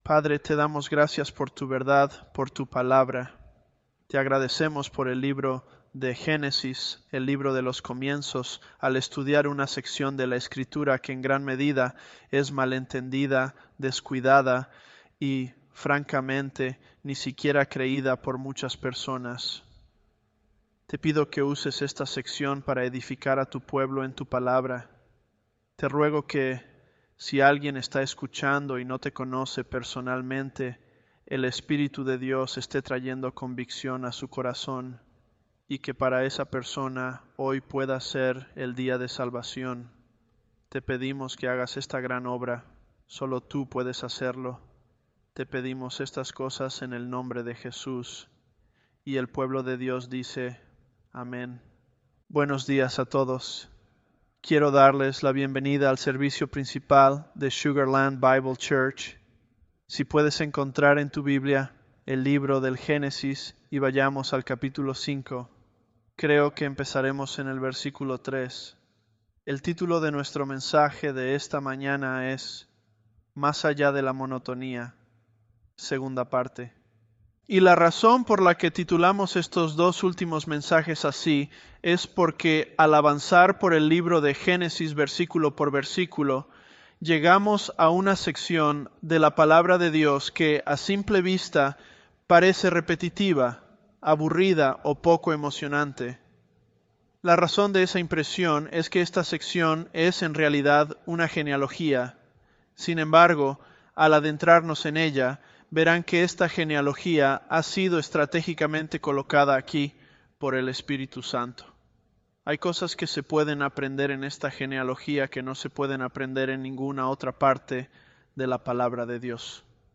ElevenLabs_Genesis-Spanish022.mp3